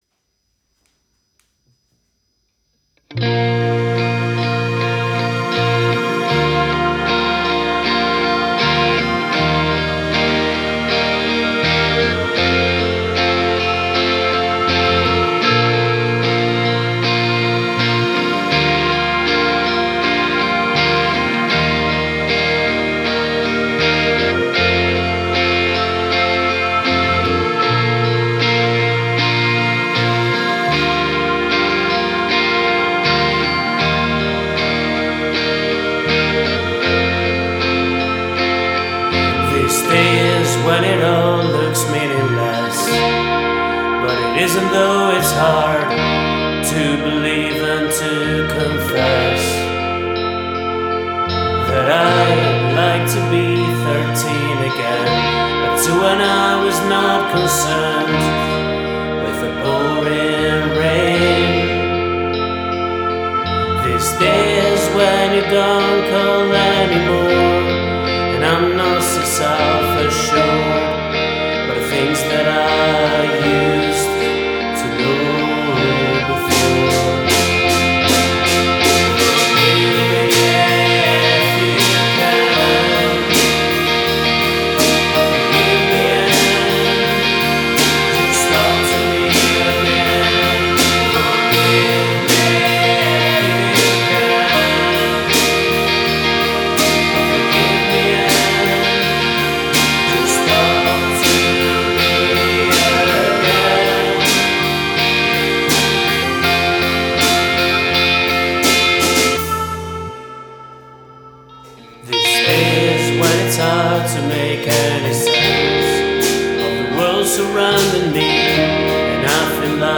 vocals, guitars, bass, keyboards, drums